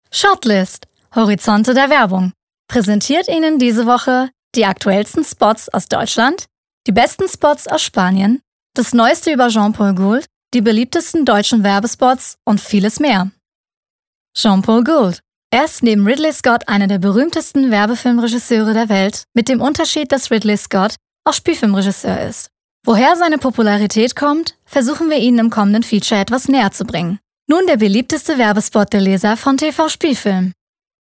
Sprecherin Synchron, Werbung, TV, Kino, Funk, Voice-Over, Höhrbuch, Hörspiel, Online-Games, Native Speaker Deutsch und Türkisch
Sprechprobe: eLearning (Muttersprache):